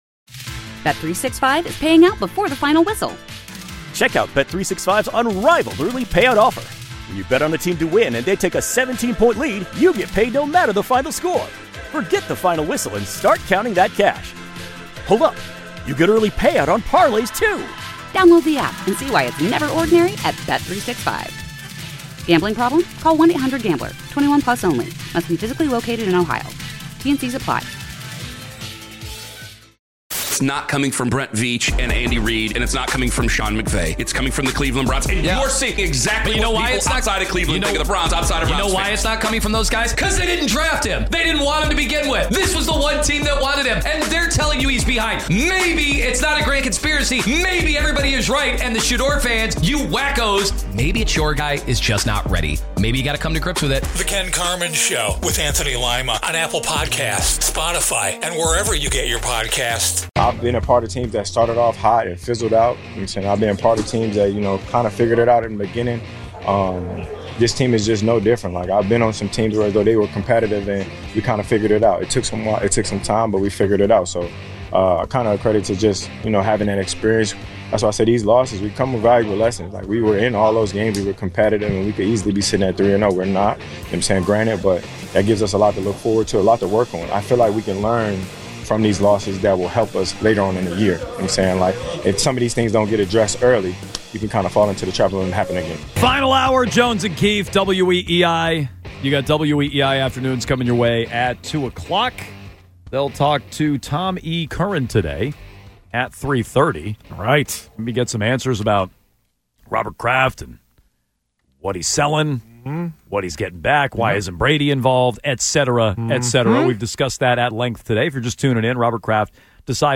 airs live weekdays 10 a.m. - 2 p.m. on WEEI-FM (the home of the Red Sox) in Boston and across the WEEI network in New England.